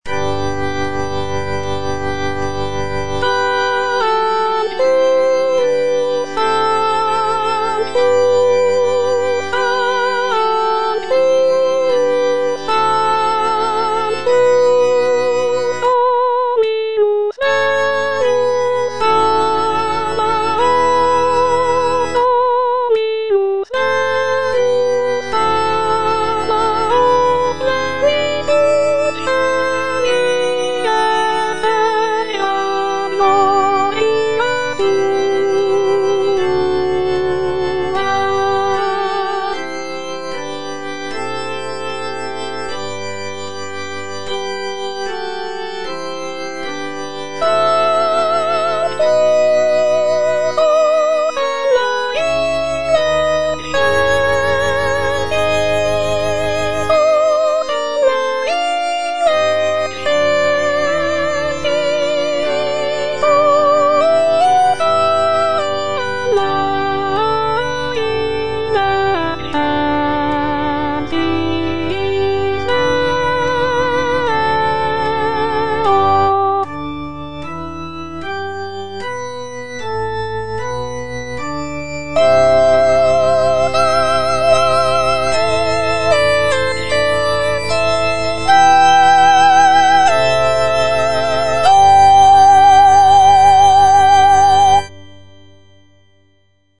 G. FAURÉ, A. MESSAGER - MESSE DES PÊCHEURS DE VILLERVILLE Sanctus (soprano I) (Voice with metronome) Ads stop: auto-stop
The composition is a short and simple mass setting, featuring delicate melodies and lush harmonies.